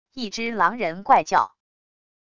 一只狼人怪叫wav音频